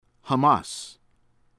HAMAS hah-MAHSS